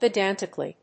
音節pe･dan･ti･cal･ly発音記号・読み方pɪdǽntɪk(ə)li